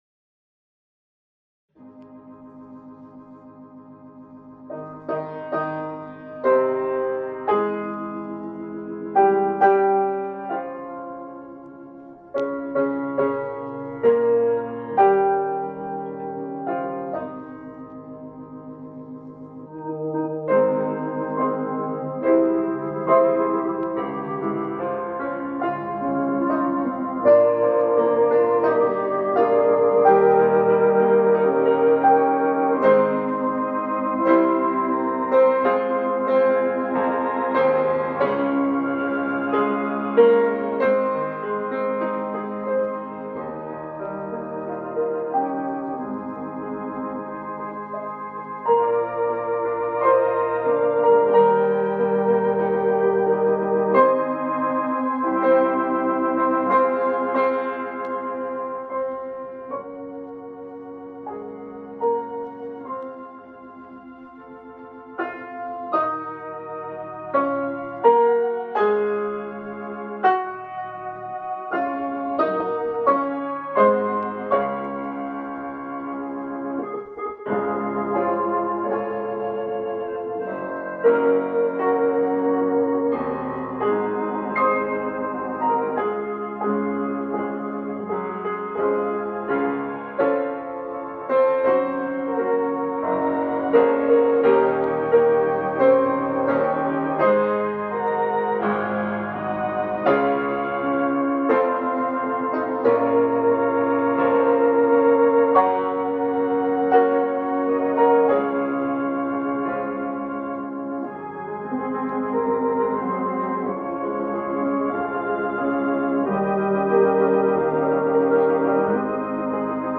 organist
pianist